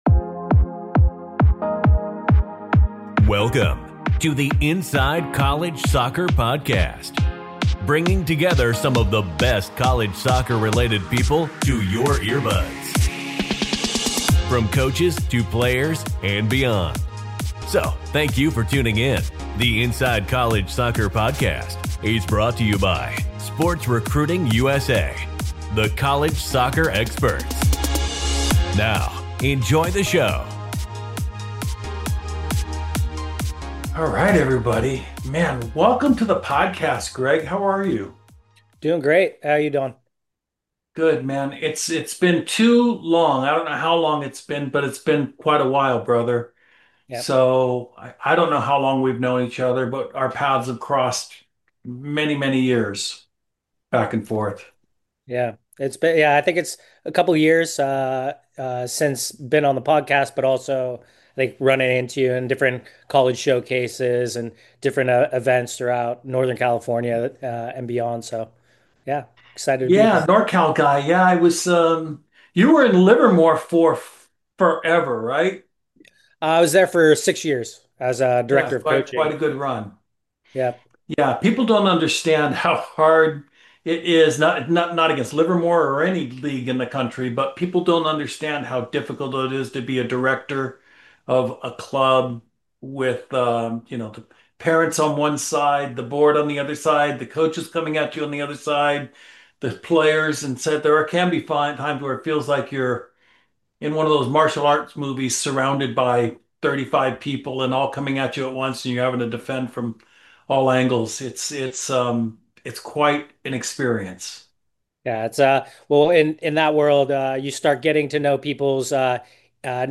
It’s an inspiring conversation about the evolution of American soccer and the people creating lasting change from the grassroots up.